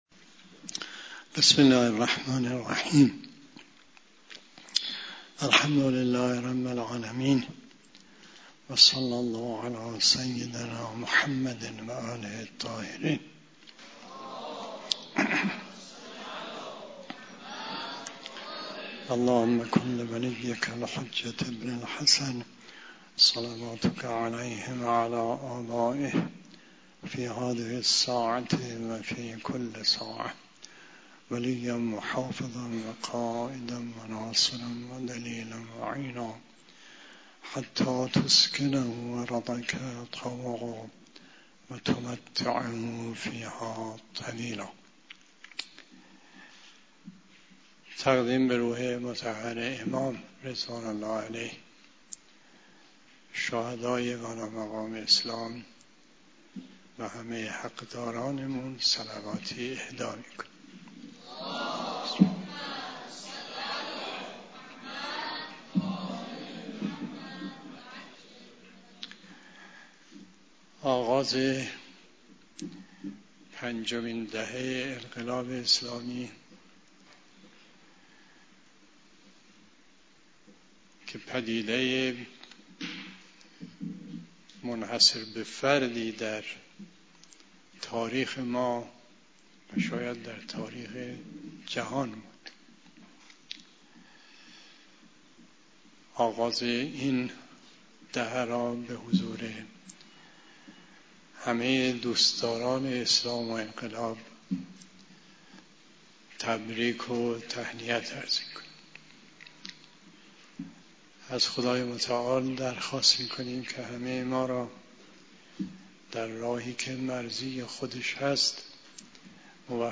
بیانات ارزشمند آیت الله مصباح یزدی (ره) در مورد "تکلیف انسان بعد از بلوغ"